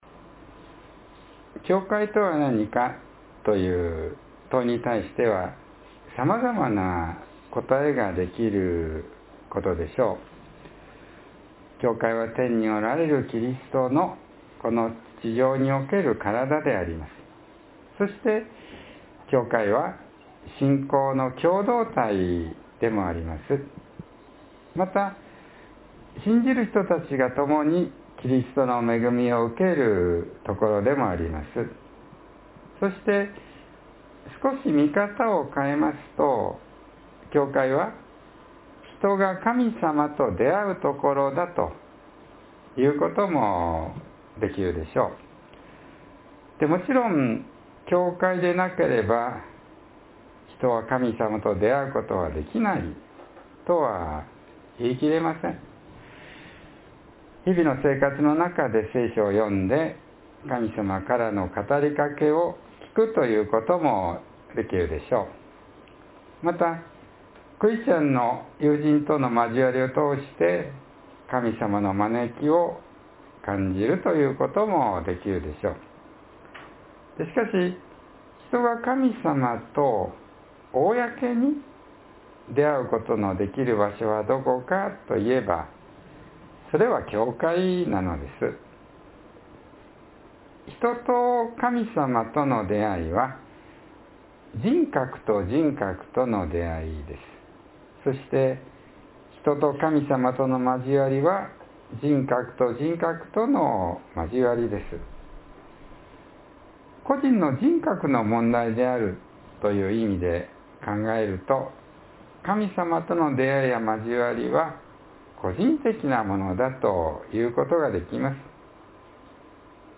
（1月25日の説教より）